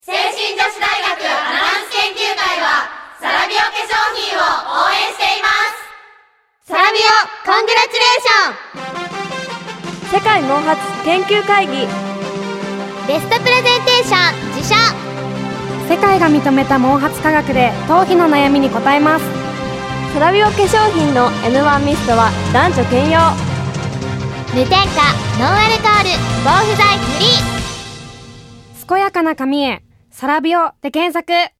聖心女子大学アナウンス研究会による「ラジオCM」収録！
どれも清々しいお声で、皆さんの笑顔が浮かぶステキなＣＭです！